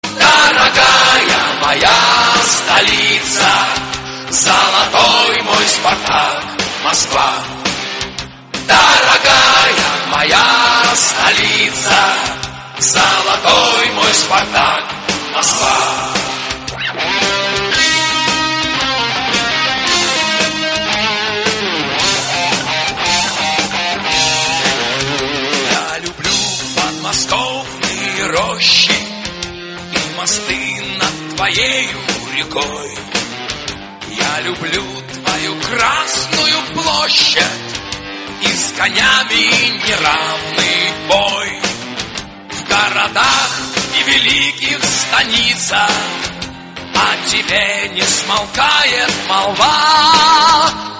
• Качество: 64, Stereo
фанатские